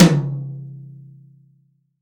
Index of /90_sSampleCDs/AKAI S6000 CD-ROM - Volume 3/Drum_Kit/DRY_KIT1
H-TOM10C-1-S.WAV